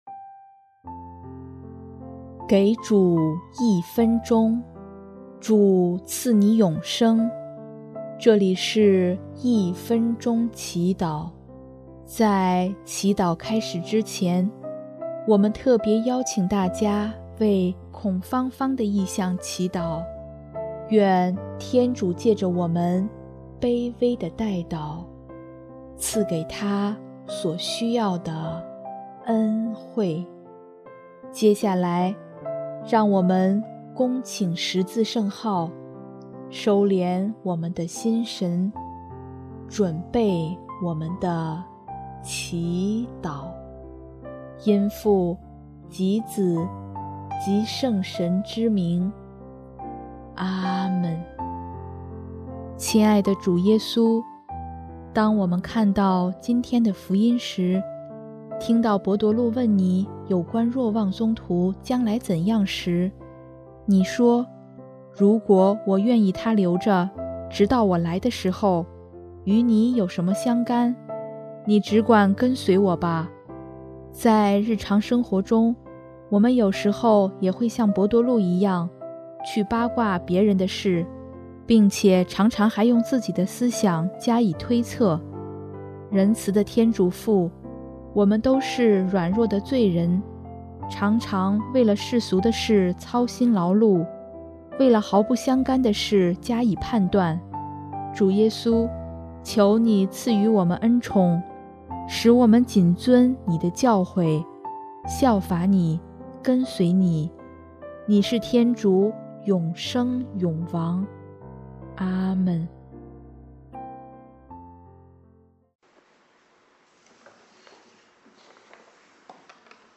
音乐：第二届华语圣歌大赛参赛歌曲《你来跟随我》